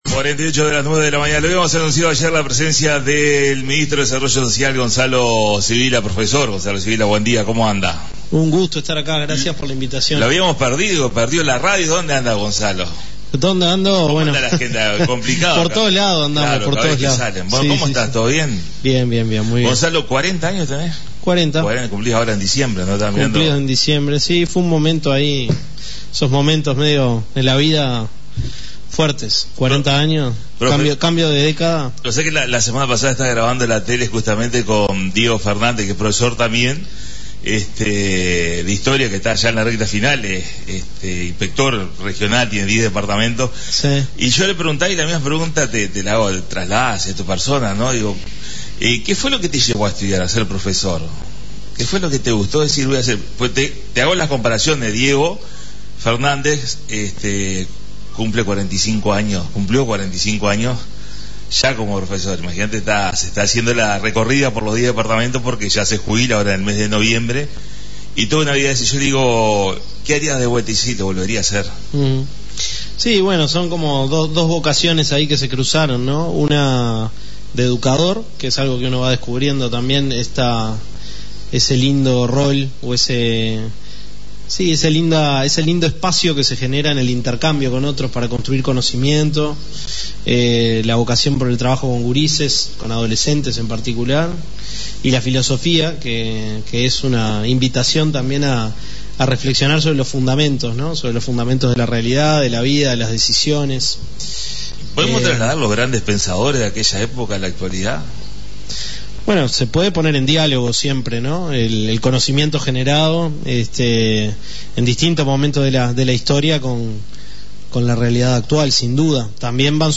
La visita a la Radio del Profesor Gonzalo Civila Ministro del Ministerio de Desarrollo Social en su visita a Salto